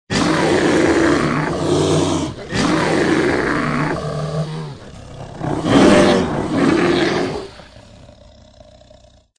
На этой странице собраны звуки полярного медведя – мощные рыки, ворчание и шаги по снегу.
1. Грозный рык медведя n2. Медвежий рык в лесу n3. Рык бурого медведя n4. Глухой рык медведя n5. Могучий рык медведя n6. Рык разъяренного медведя n7. Устрашающий рык медведя n8. Рык лесного великана n9. Громкий рык медведя n10. Дикий рык медведя